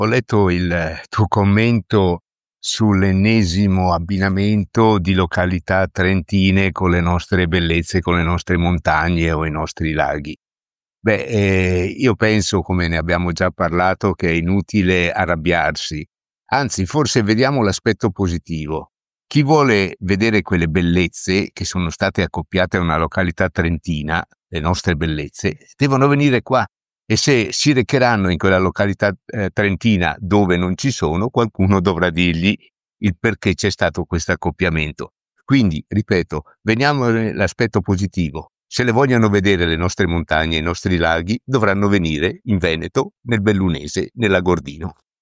IL COMMENTO DEL SINDACO DI ALLEGHE, DANILO DE TONI
danilo-sindaco-misurina-1.mp3